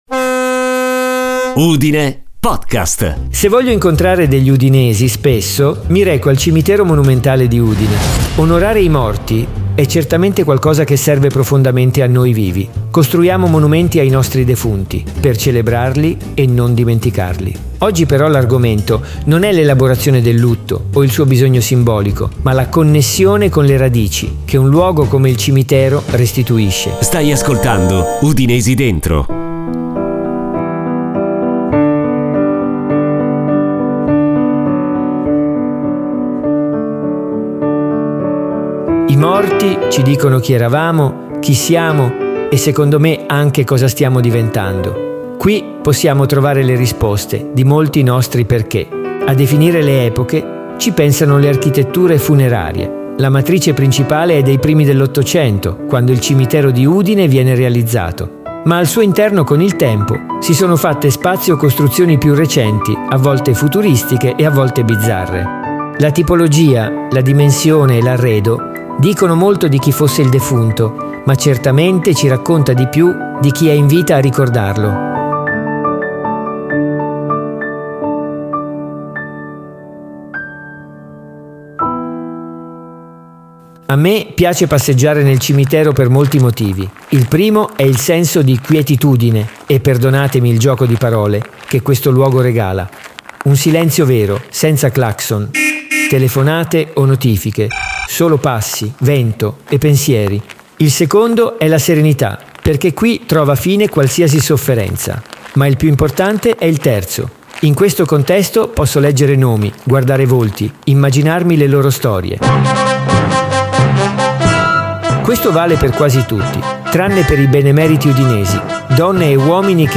Yann Tiersen – “Comptine d’un autre été”
Blade Runner Soundtrack